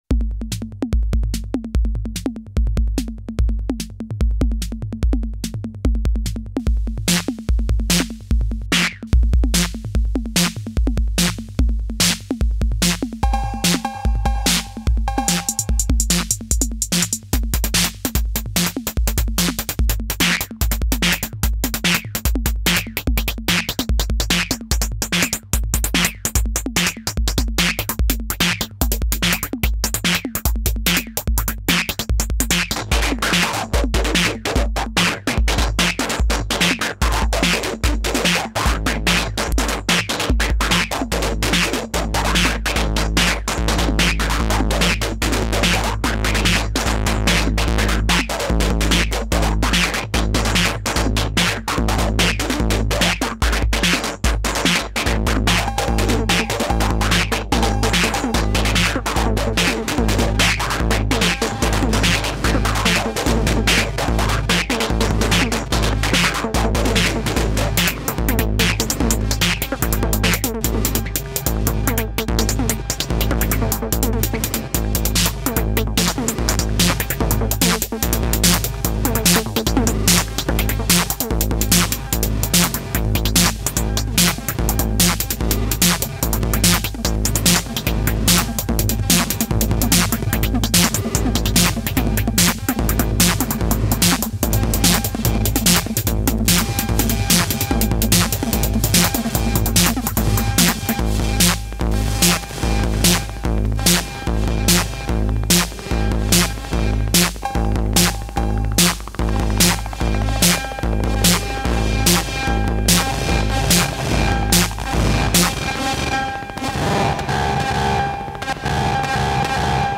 Patchbay going wild! I sequenced a simple polymetric pattern on the Squarp Pyramid triggering the Behringer RD-8 drum machine. Kick/snare are in 8s, hats in 5s, toms in 7s and cowbell in 9s (I think!) and patched in all kinds of silly noises.
• Kick is going through annihilator fuzz
• Hats are going through Green Ringer and Moon Phaser and a J Mascis mod Muff
• Snare is going through a Warship and Quack envelope filter
• Cowbell is going through the octave master and getting mad glitchy
• Percussion is going through a Gristleiser
rd8-fx-pedal-chaos.mp3